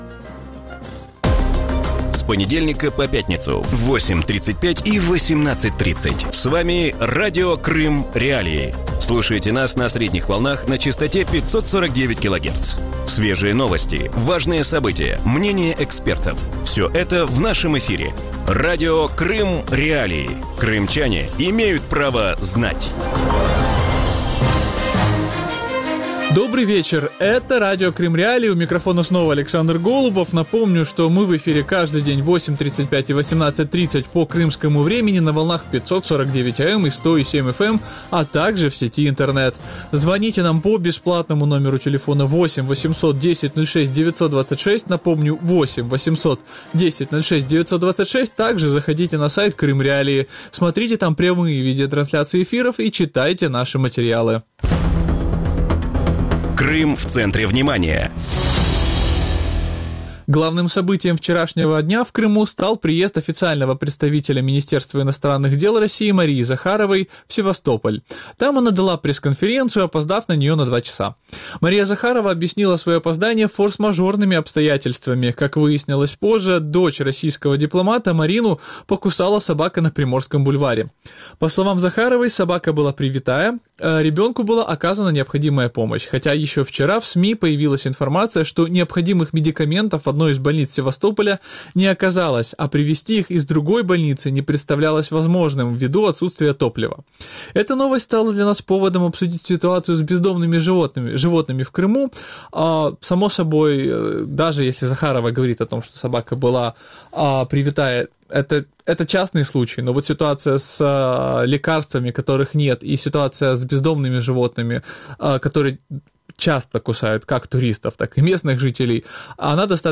В вечернем эфире Радио Крым.Реалии обсуждают ситуацию с бездомными животными на аннексированном полуострове. Какое количество бездомных животных обитает в крупнейших городах Крыма?